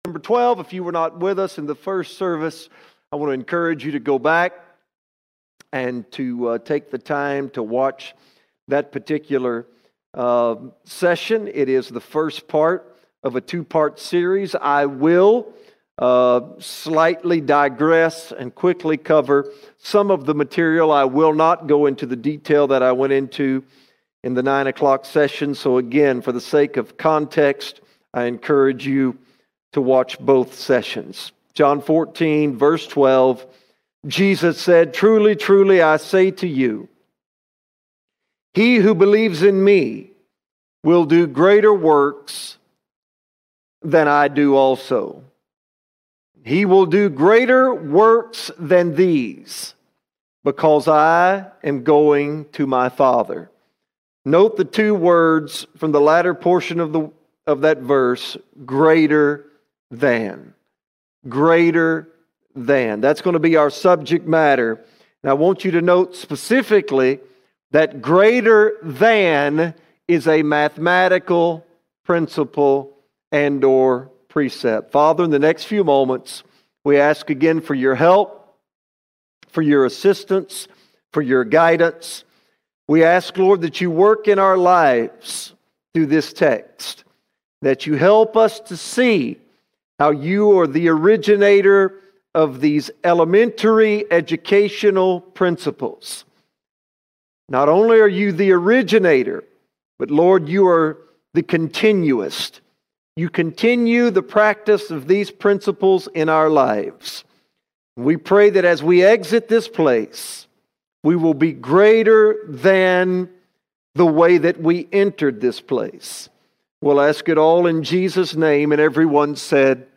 31 March 2025 Series: Guest Speakers All Sermons Greater Than> Part 2 Greater Than> Part 2 Sometimes Jesus adds to our lives, sometimes He subtracts.